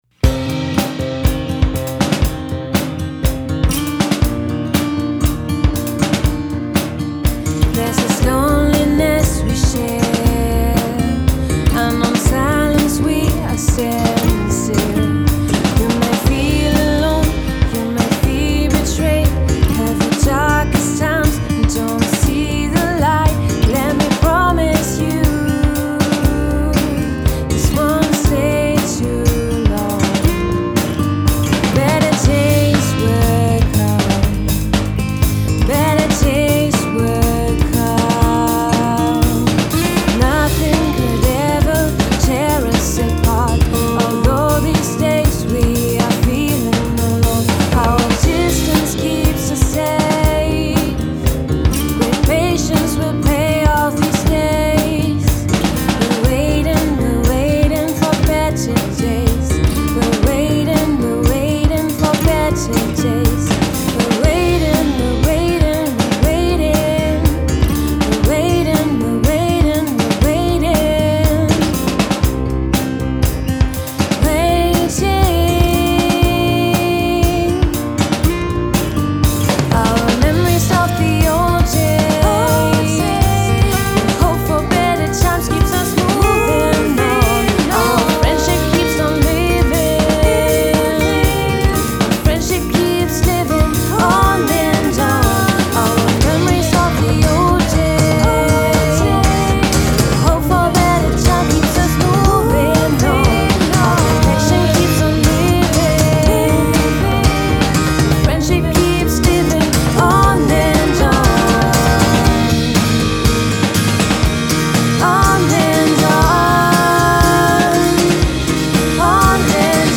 Wie ein Chamäleon passen wir uns den aktuellen Gegebenheiten an und haben von März bis Mai eine virtuelle Jamsession organisiert.
Im Anschluss haben wir das Ergebnis professionell vertont und ein Foto der Jammer:in erstellt.
Schlagzeug
Akustikgitarre
Bass
Gesang A